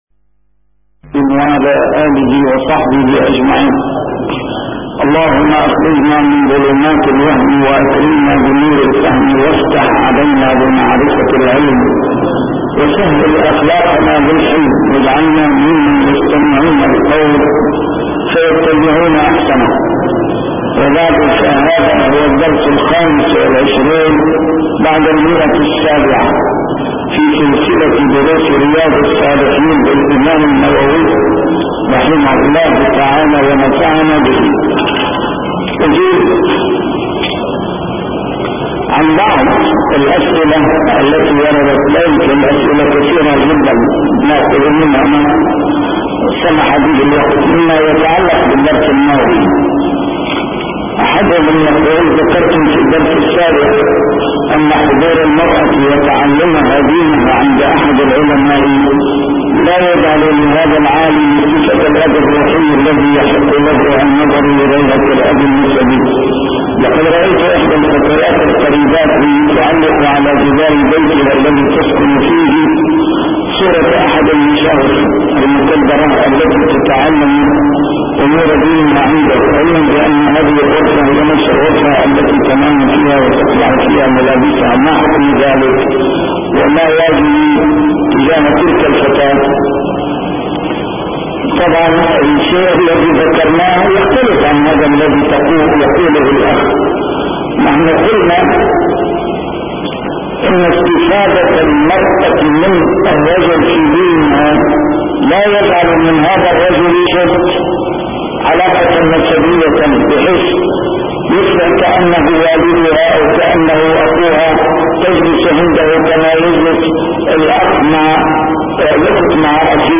A MARTYR SCHOLAR: IMAM MUHAMMAD SAEED RAMADAN AL-BOUTI - الدروس العلمية - شرح كتاب رياض الصالحين - 725- شرح رياض الصالحين: عند المرور بقبور الظالمين